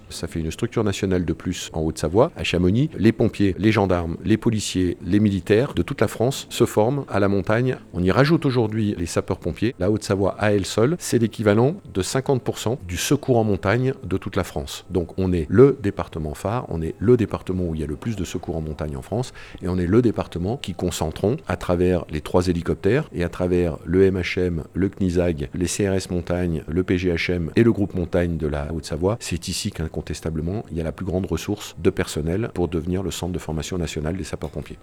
Lors de la signature, Martial Saddier, président du conseil départemental de la Haute-Savoie et du service départemental d'incendie et de secours (SDIS 74) a déclaré que Chamonix était un choix évident pour implanter ce centre national.